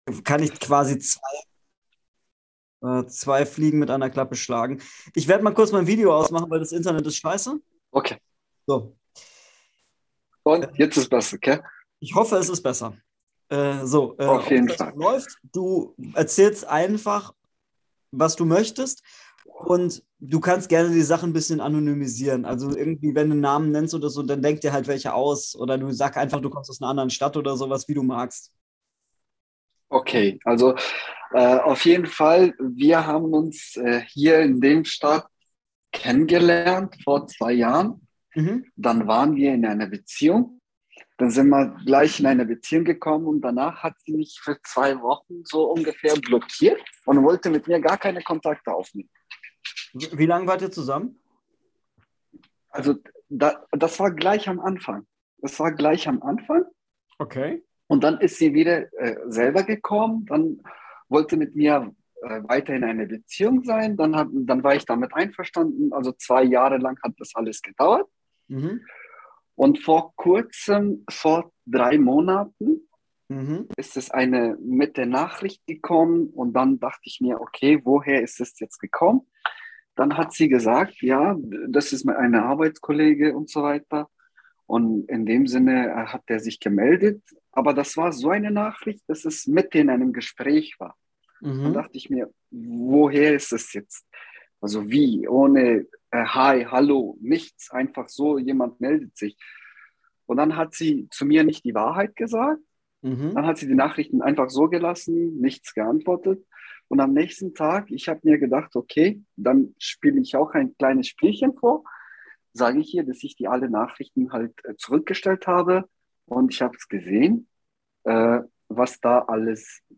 Heute einen Gast im Gespräch. Er erzählt von seiner gescheiterten Beziehung und fragt, was er jetzt tun soll.